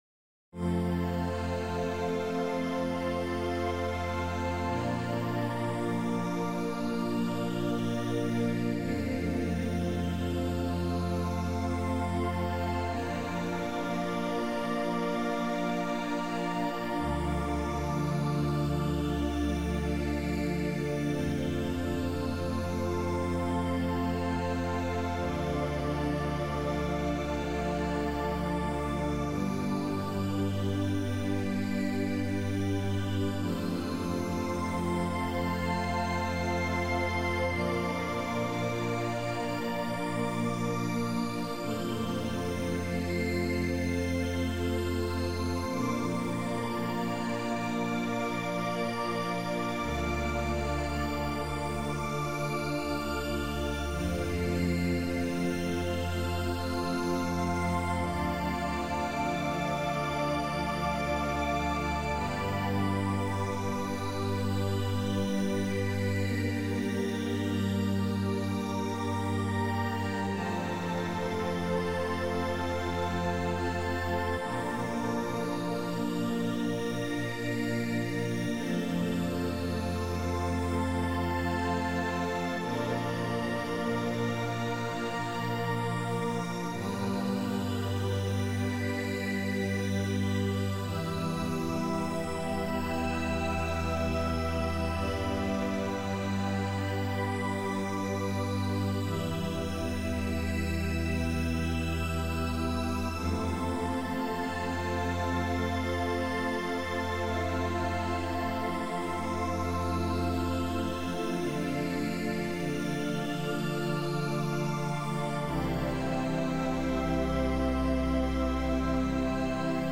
Deep Ambient
Deep-ambient.mp3